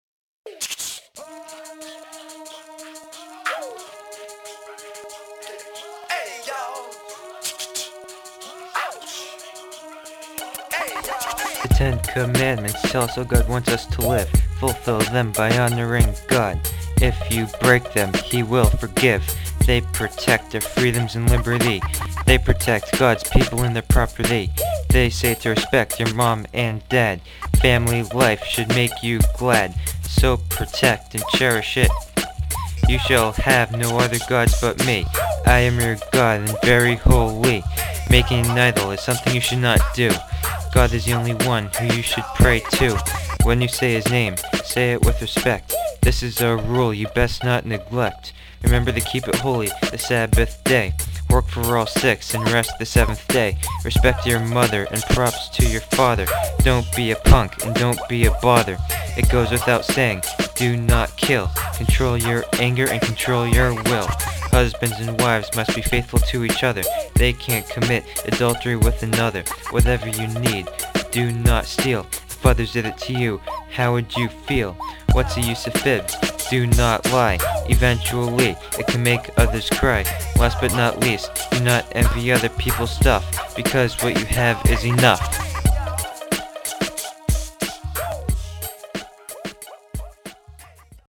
Youth Sunday Skit
original rap song
10commandmentrap.mp3